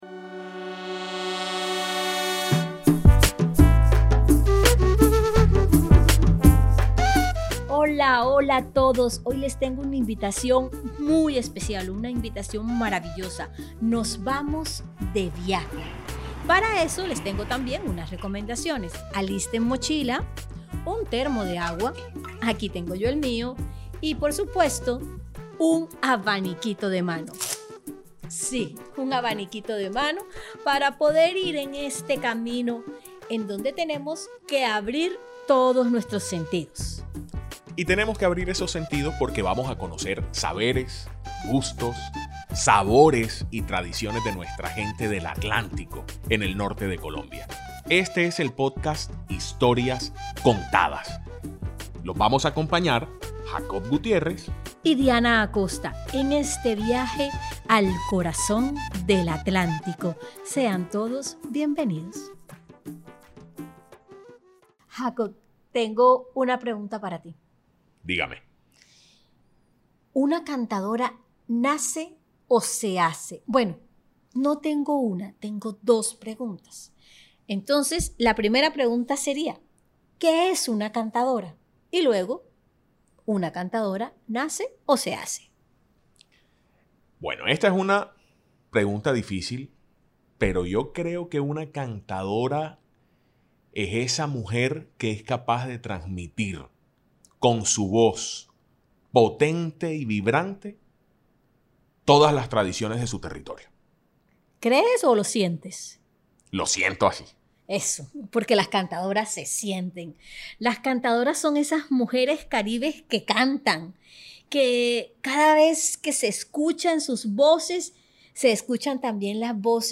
Una mujer cuenta cómo desde niña soñaba con ser artista, cómo persiguió sus sueños, llegó a recorrer escenarios internacionales, y un día cumplió su deseo de presentarse en el lugar donde Gabriel García Márquez recibiría su premio Nobel de Literatura.